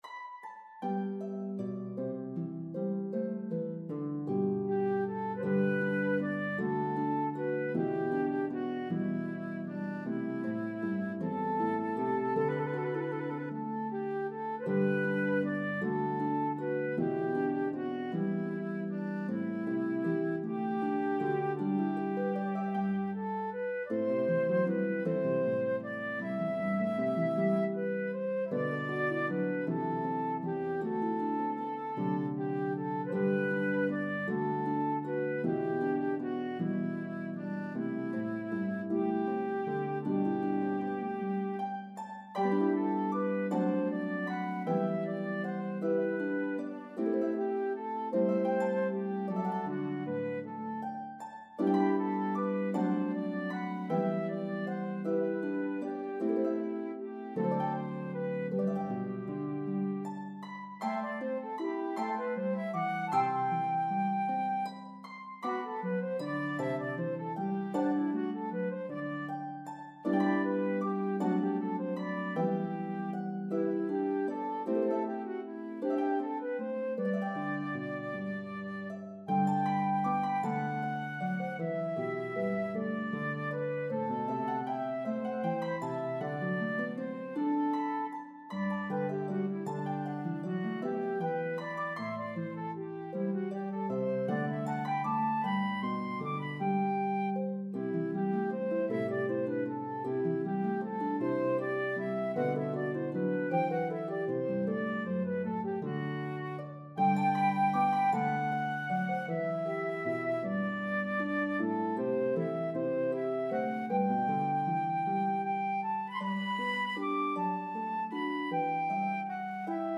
Harp and Flute version